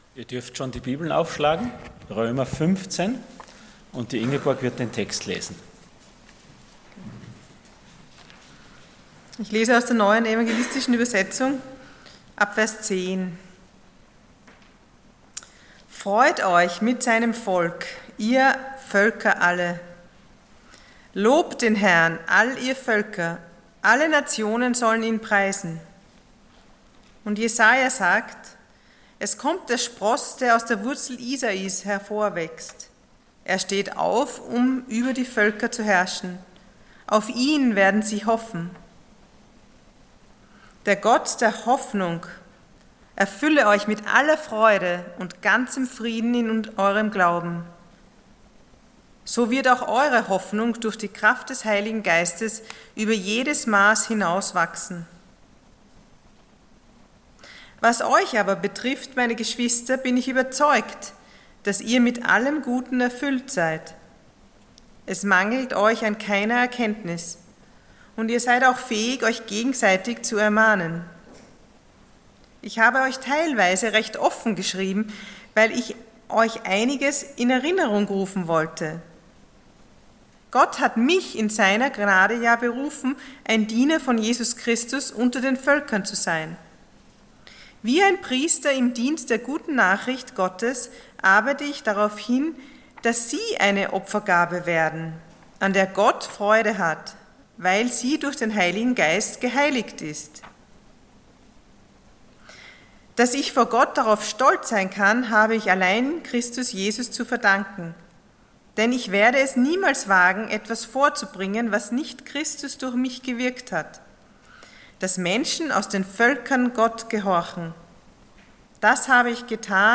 Passage: Römer 15,14-22 Dienstart: Sonntag Morgen